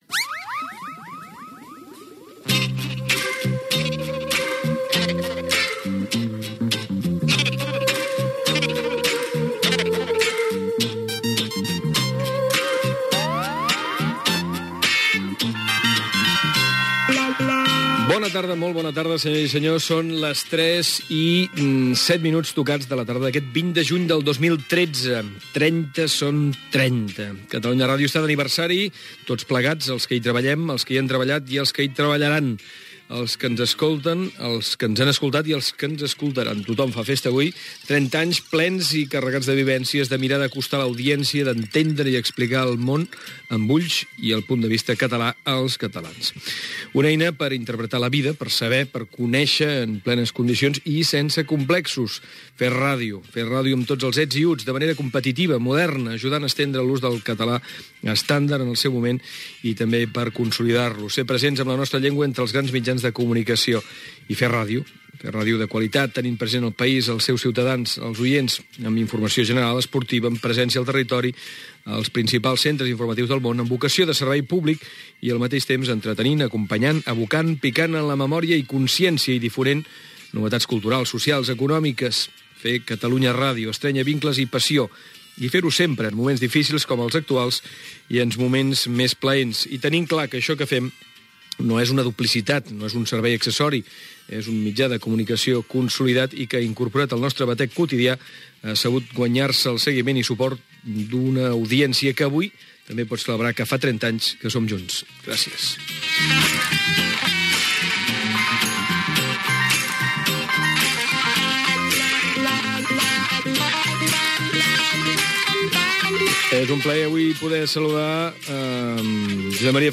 Indicatiu de l'emissora
Informatiu